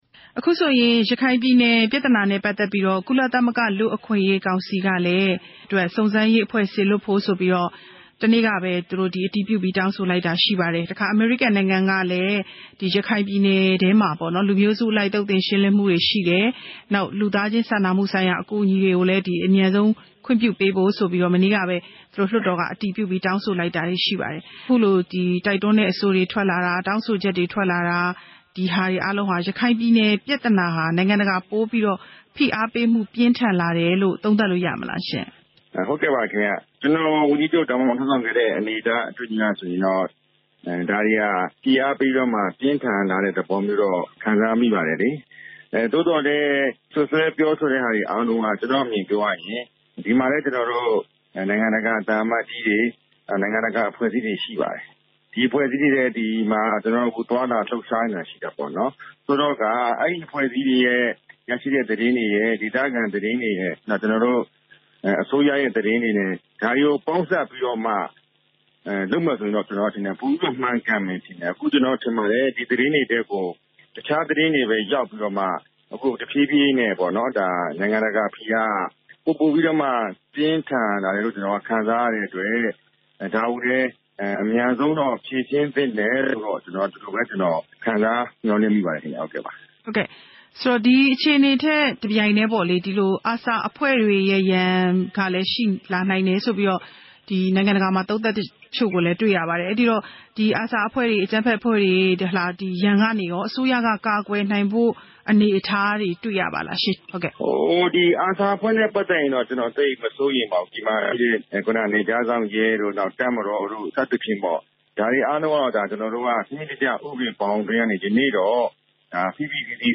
ရခိုင်ပြည်နယ်အရေးနဲ့ ပတ်သက်ပြီး ဝန်ကြီးချုပ်ဟောင်း ဦးမောင်မောင်အုန်းနဲ့ မေးမြန်းချက်